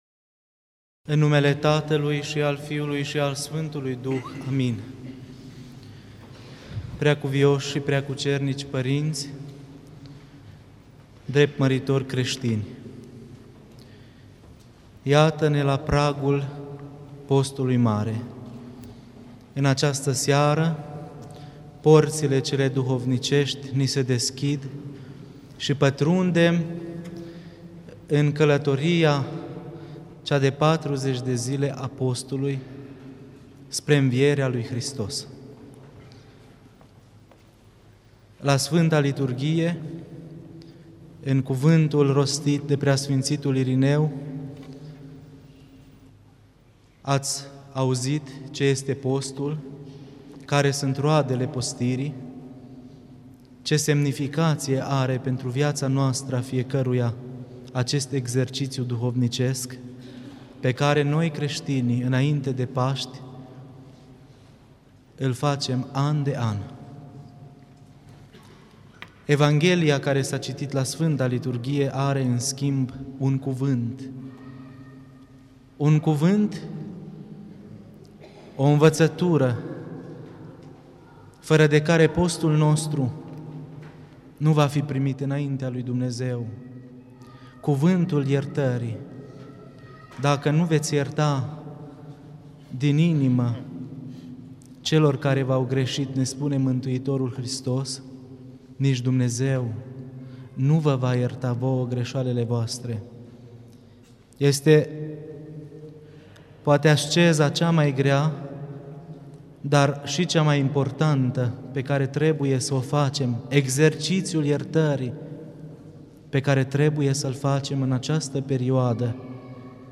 Predică la Duminica Izgonirii lui Adam din Rai